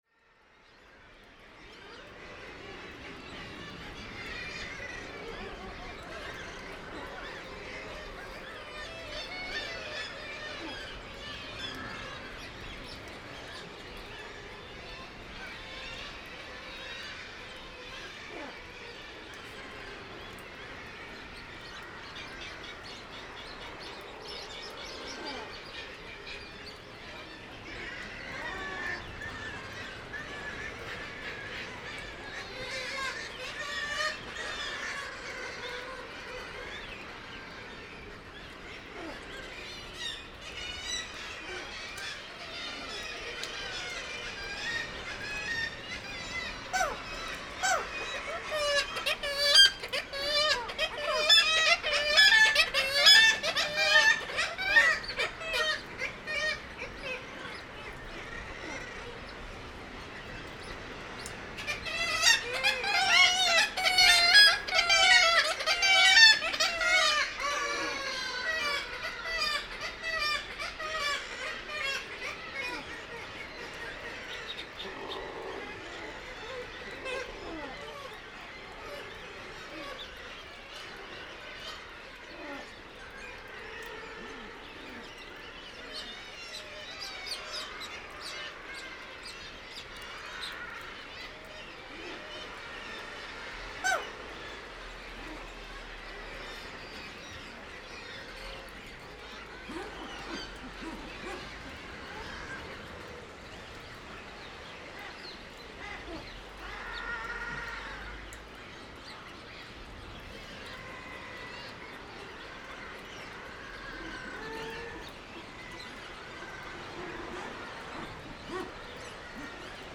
It is Europe’s largest bird cliff, 14km long and up to 440m high.(wikipedia) This recording was made on the cliff edge in Ritugjá (Kittiwake Canyon), ca 90 meters above sea level and several hundreds meters fron Bjargtangar lighthouse.